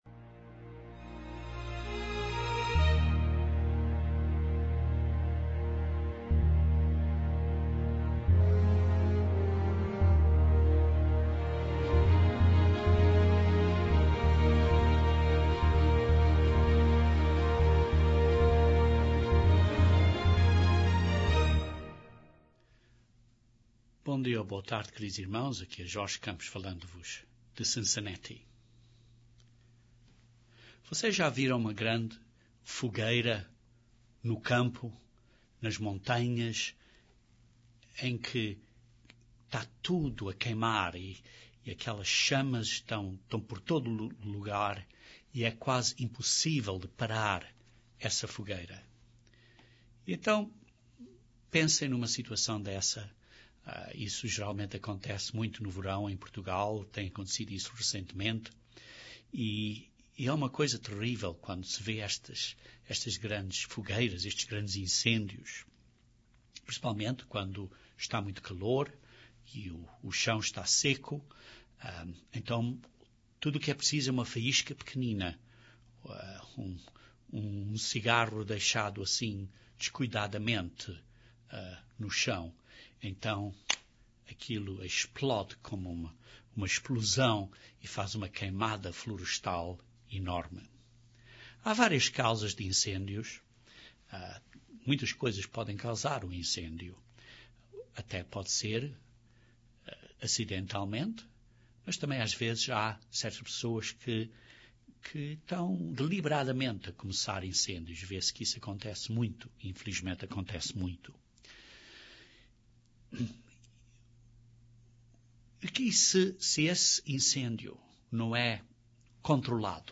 No entanto precisamos de ter cuidado e não nos involvermos na luta errada. Este sermão descreve 4 pontos proféticos importantes a considerar para nos orientarmos.